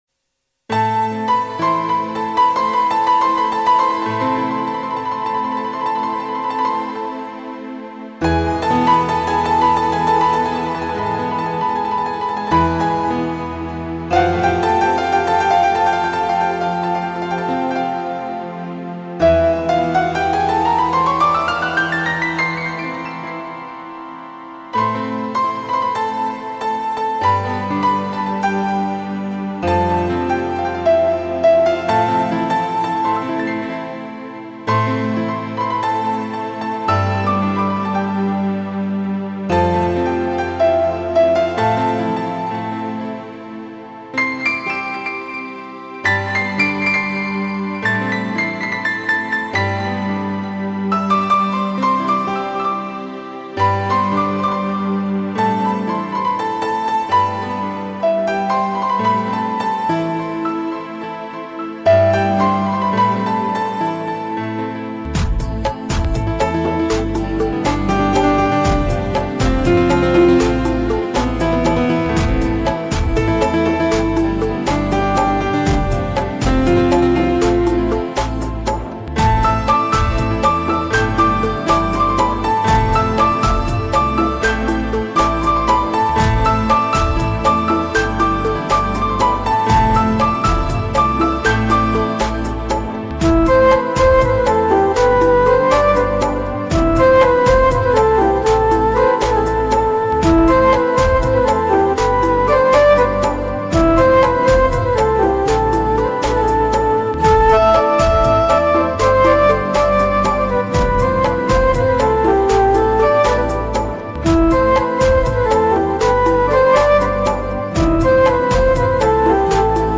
Instrumental Music And Rhythm Track Songs Download